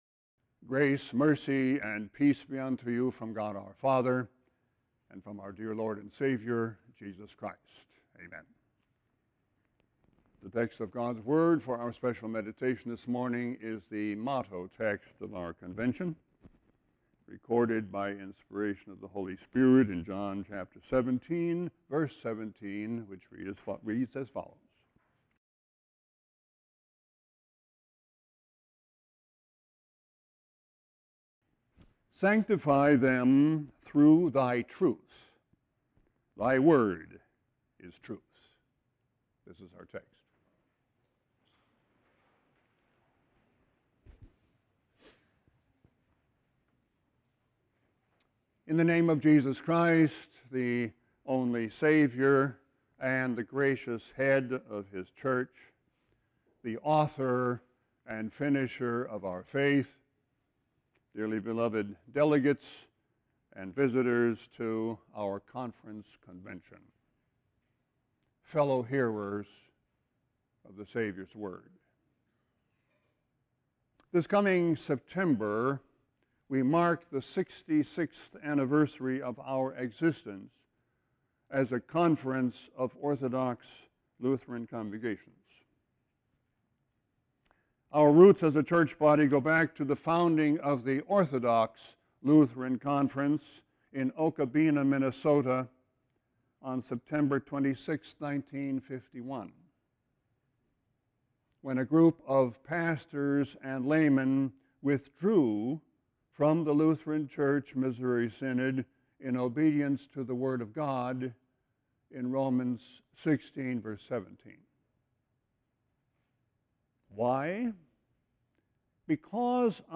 Sermon-6-23-17.mp3